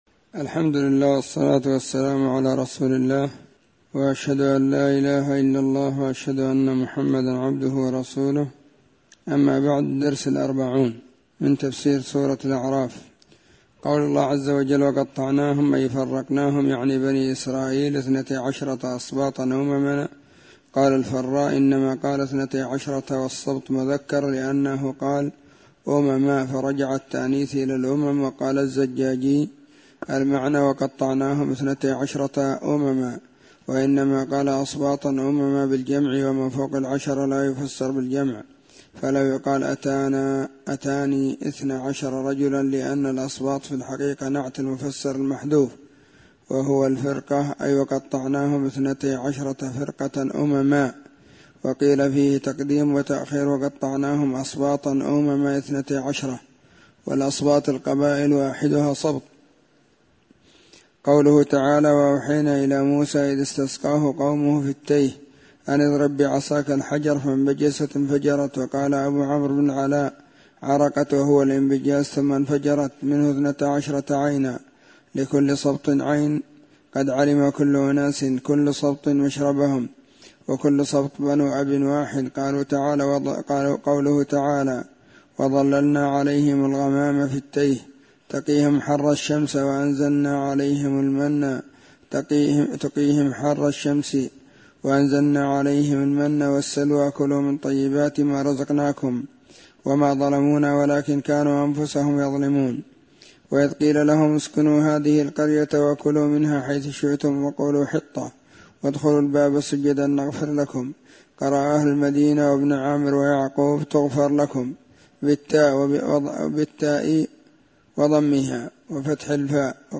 🕐 [بعد صلاة الظهر]
📢 مسجد الصحابة – بالغيضة – المهرة، اليمن حرسها الله.